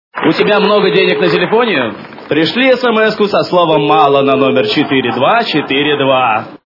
» Звуки » Смешные » У тебя много денег на телефоне - Отправь сообщение со словом Мало
При прослушивании У тебя много денег на телефоне - Отправь сообщение со словом Мало качество понижено и присутствуют гудки.